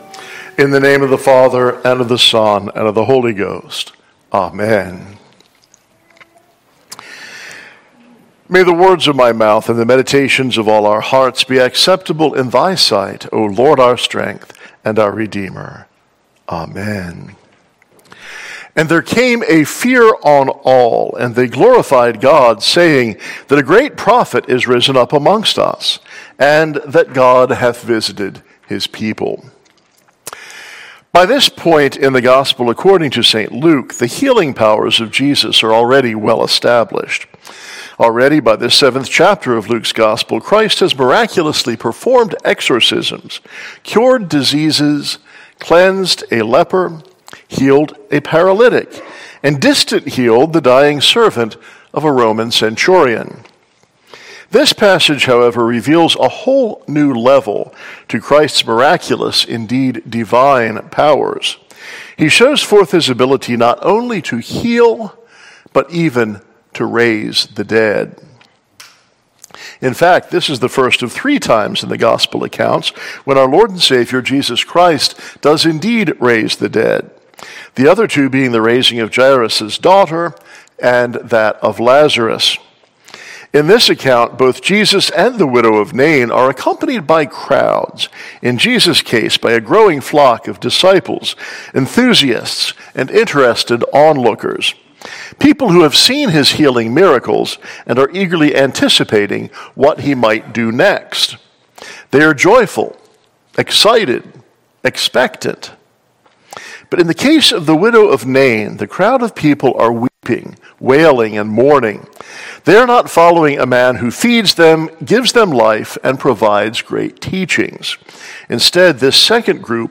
Listen to the sermon for the Sixteenth Sunday after Trinity.